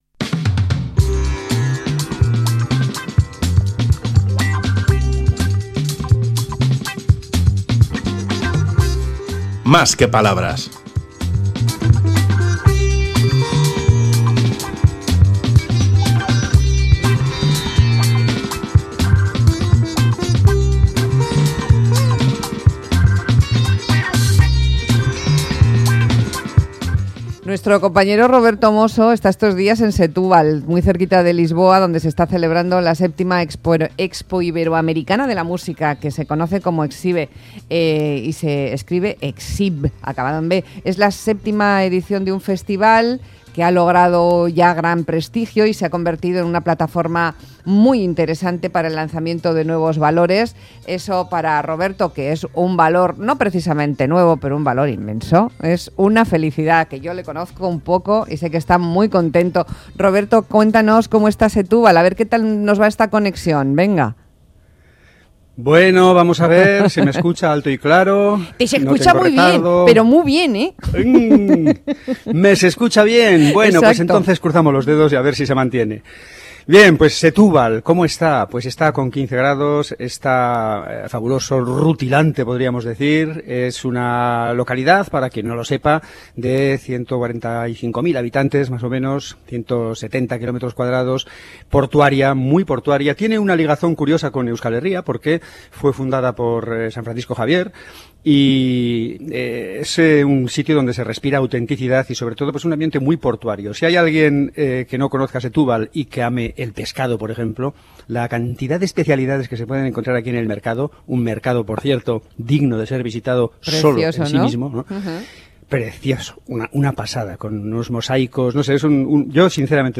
Audio: Conexión desde EXIB 2021